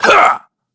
Shakmeh-Vox_Attack2_kr.wav